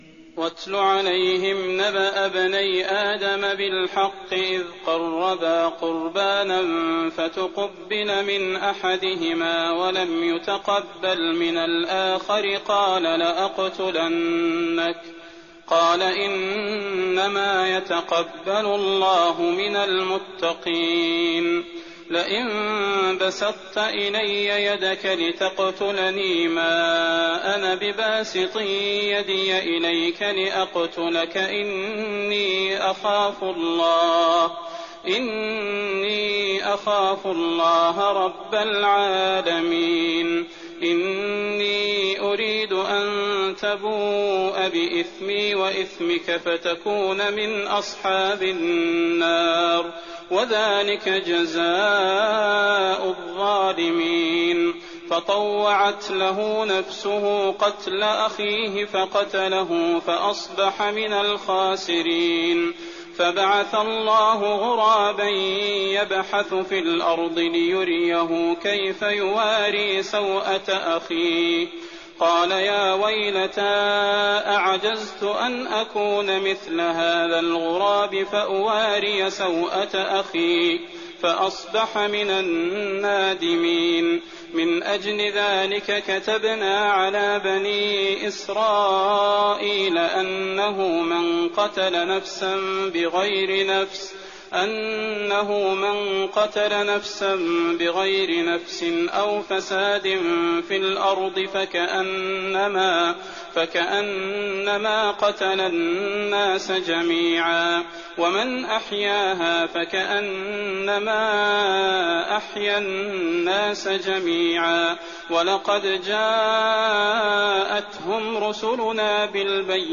تراويح الليلة السادسة رمضان 1419هـ من سورة المائدة (27-81) Taraweeh 6th night Ramadan 1419H from Surah AlMa'idah > تراويح الحرم النبوي عام 1419 🕌 > التراويح - تلاوات الحرمين